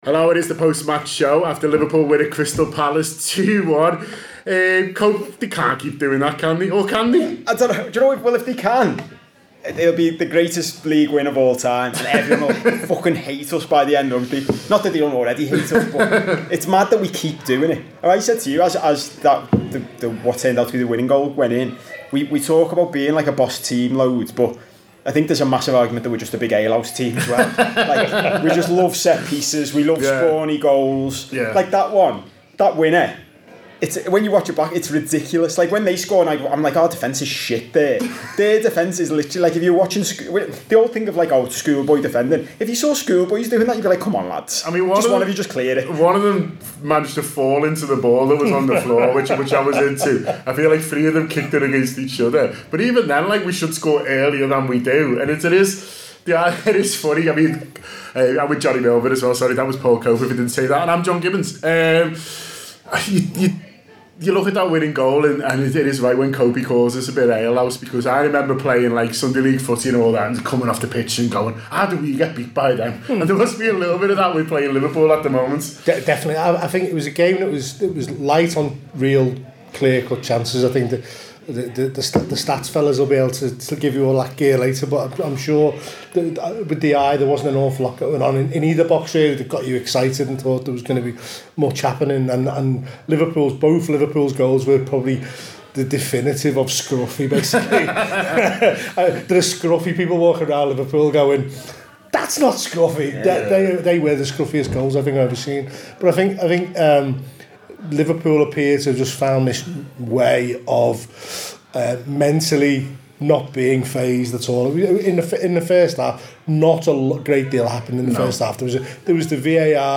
to give their post-match reaction